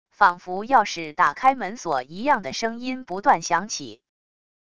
仿佛钥匙打开门锁一样的声音不断响起wav音频